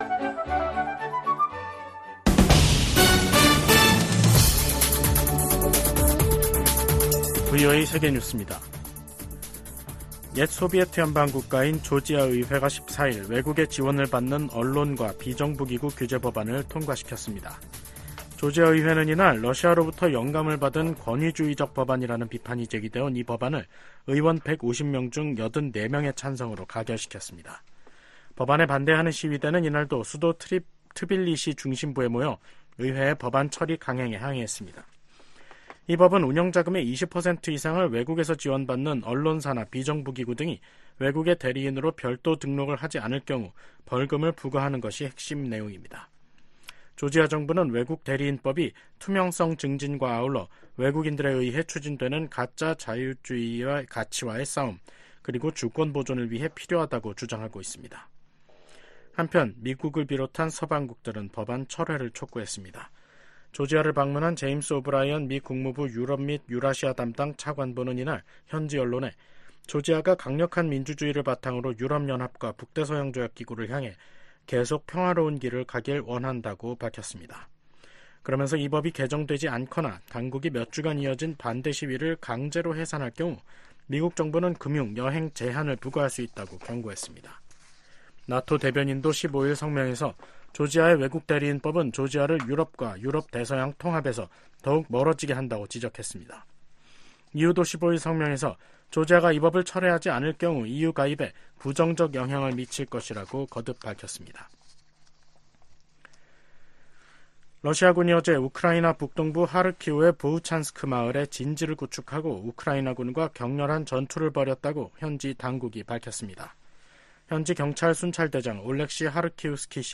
VOA 한국어 간판 뉴스 프로그램 '뉴스 투데이', 2024년 5월 15일 3부 방송입니다. 우크라이나를 방문 중인 토니 블링컨 미 국무장관이 14일 러시아의 침략 전쟁과 이를 지원하는 북한, 이란을 비판했습니다. 북한의 미사일 도발 가속화로 인해 안보 태세를 강화하는 것 외에는 다른 선택지가 없다고 국무부 동아태 담당 차관보가 말했습니다.